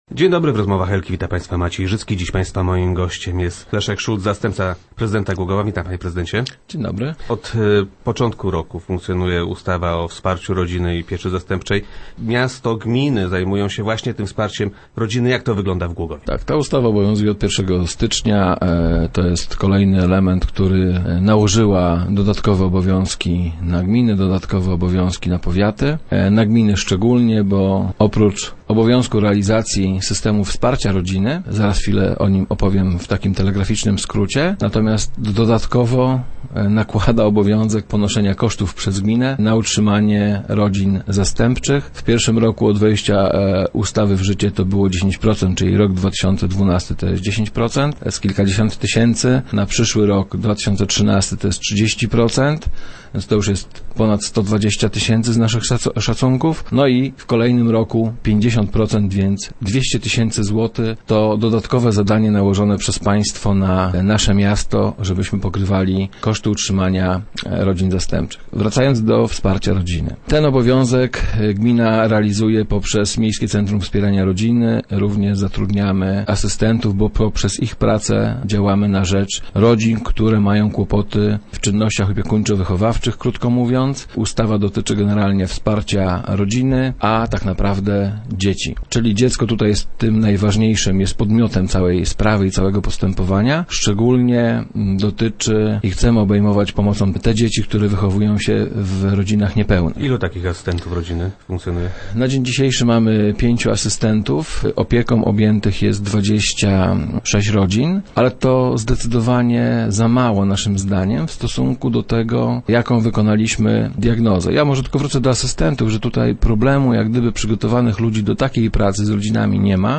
Jej brak trochę nas dziwi - twierdzi wiceprezydent Szulc, który był gościem Rozmów Elki